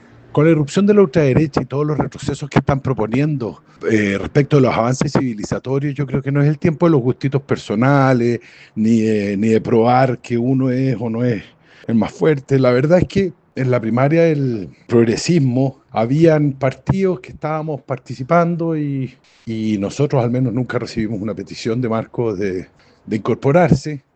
Por su parte, el timonel del Partido Liberal, Juan Carlos Urzúa, cuestionó la decisión de Marco Enríquez-Ominami, afirmando que “no es el tiempo de los gustos personales”.